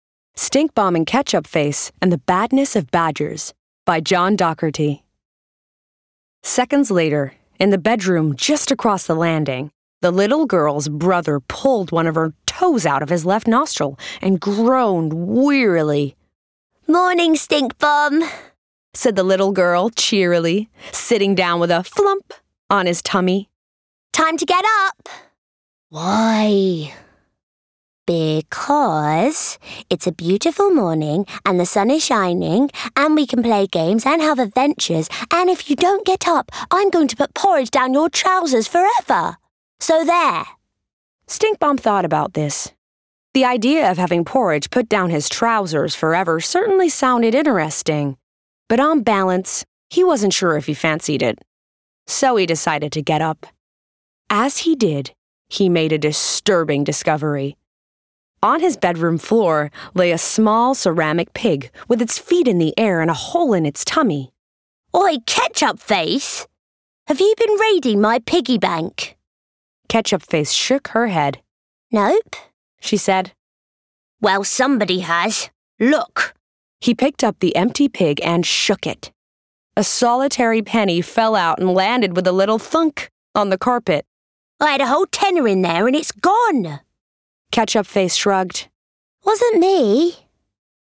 ***NEW ARTIST*** | 20s-30s | Transatlantic, Genuine & Charismatic